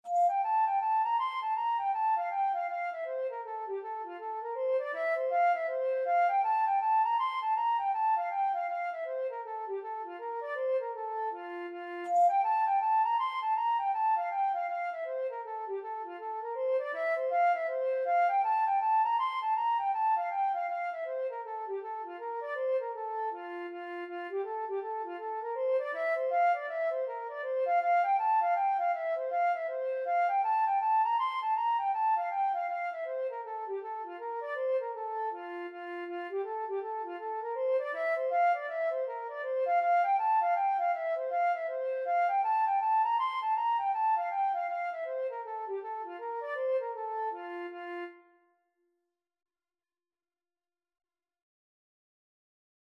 Free Sheet music for Flute
Traditional Music of unknown author.
F major (Sounding Pitch) (View more F major Music for Flute )
2/2 (View more 2/2 Music)
F5-C7
Traditional (View more Traditional Flute Music)